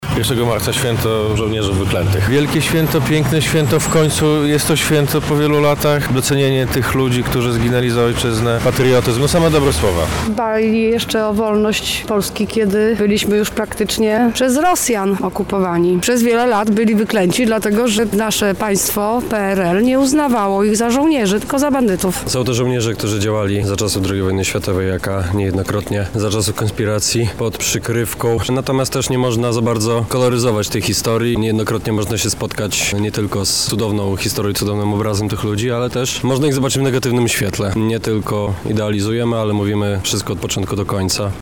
żołnierze wyklęci- sonda
– mówią mieszkańcy Lublina.